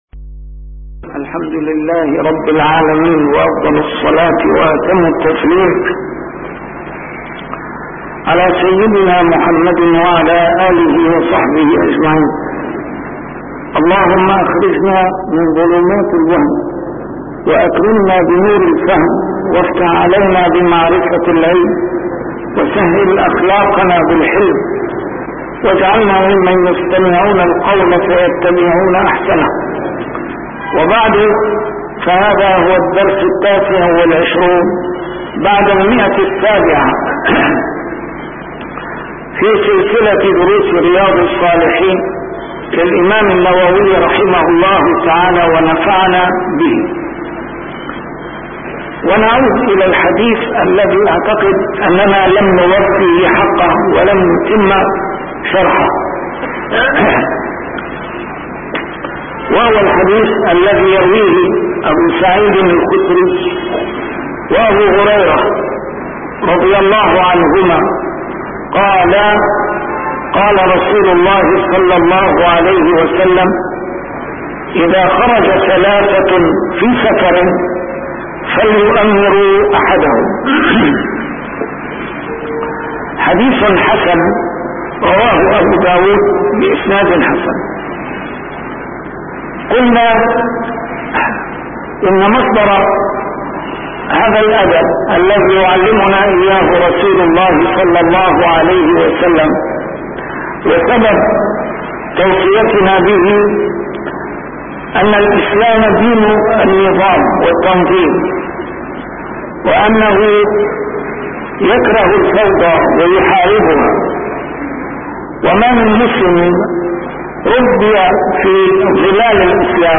A MARTYR SCHOLAR: IMAM MUHAMMAD SAEED RAMADAN AL-BOUTI - الدروس العلمية - شرح كتاب رياض الصالحين - 729- شرح رياض الصالحين: طلب الرفقة